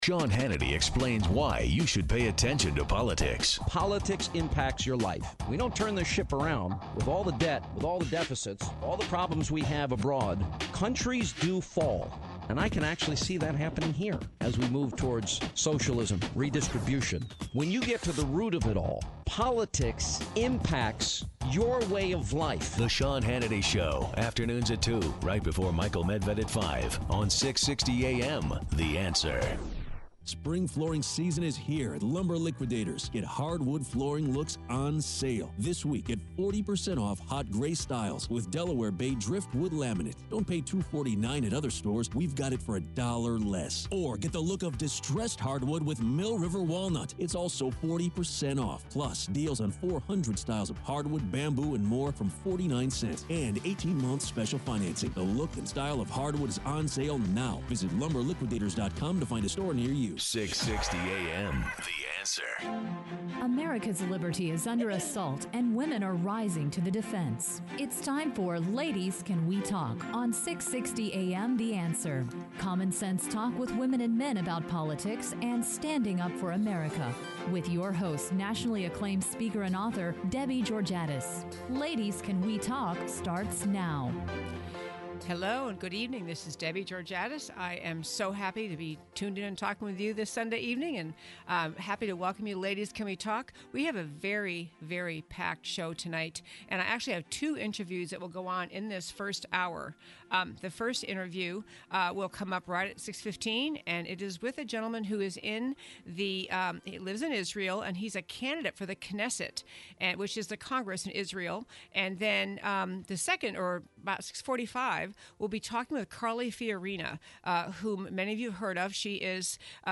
Carly Fiorina Calls In; Special Interview with Israeli candidate for the Knesset
Listen to the podcast from the first hour of our March 15th show on 660AM.